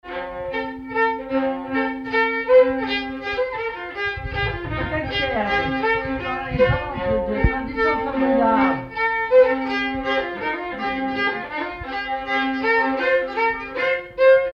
Mazurka
violon
danse : mazurka
circonstance : bal, dancerie